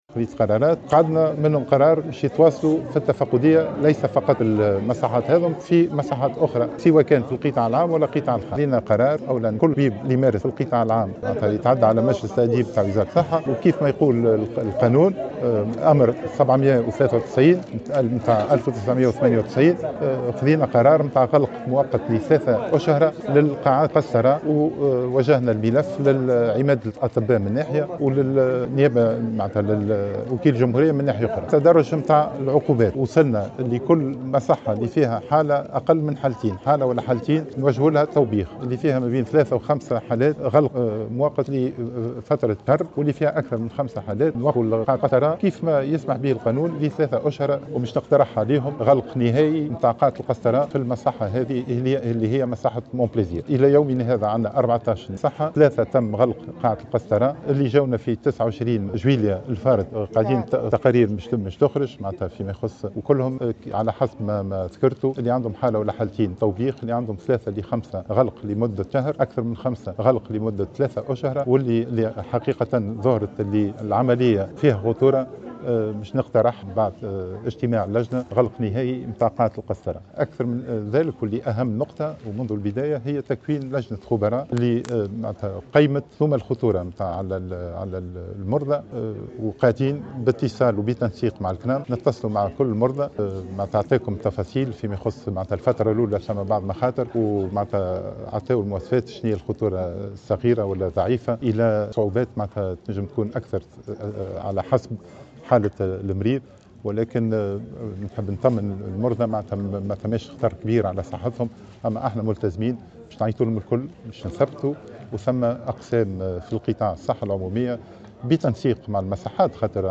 عقد وزير الصحة سعيد العايدي صباح اليوم الثلاثاء ندوة صحفية مع مجموعة من ممثلي المصالح المختصة بالقطاع حول ملف اللوالب منتهية الصلوحية .